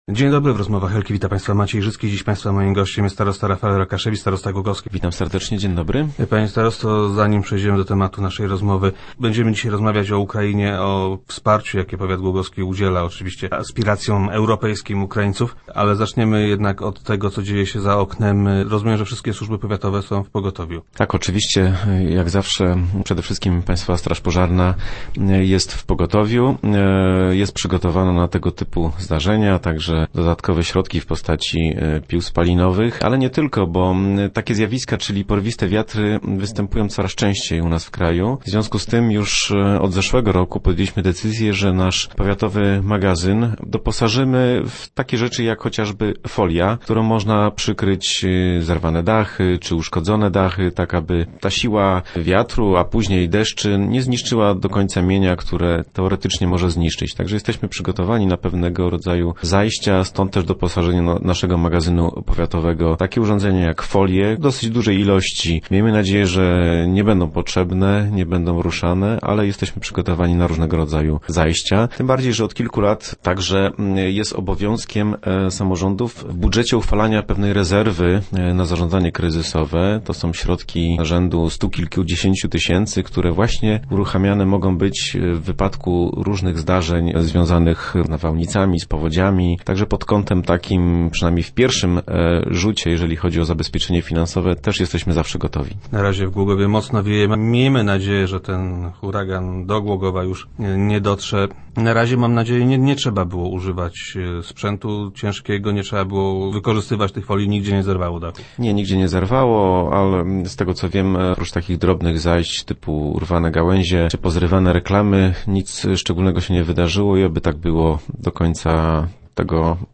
Wkrótce zaś do głogowa przyjedzie duża delegacja z zaprzyjaźnionego Kamieńca Podolskiego. Gościem Rozmów Elki był starosta Rafael Rokaszewicz.